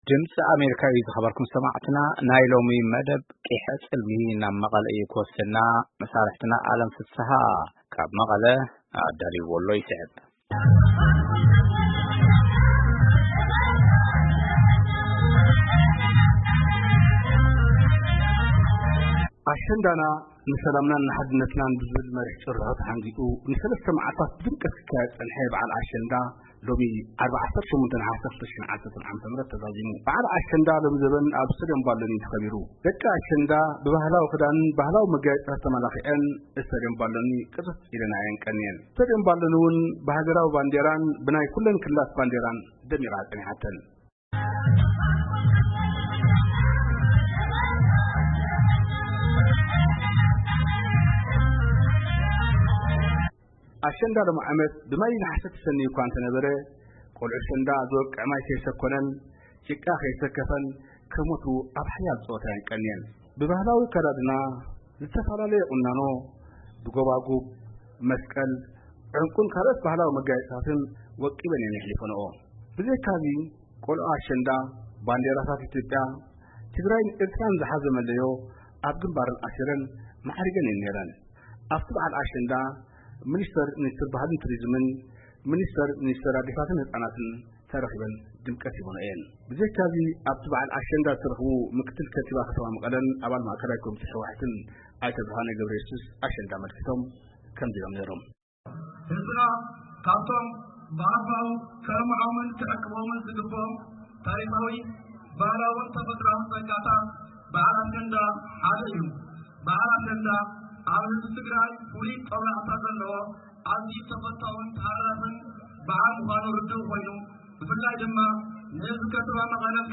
ጸብጻብ ኣከባብራ በዓል ኣሸንዳ ከተማ መቐለ